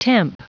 Prononciation du mot temp en anglais (fichier audio)
Prononciation du mot : temp